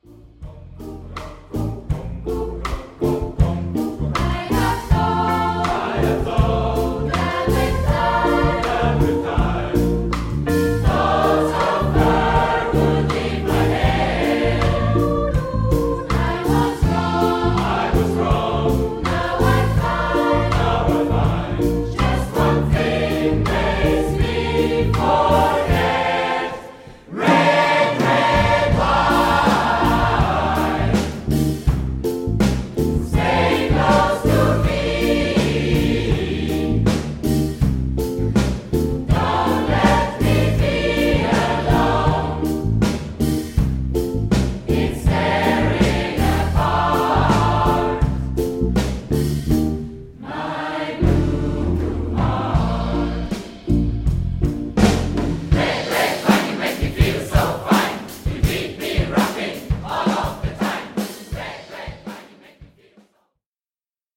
Band
SMATB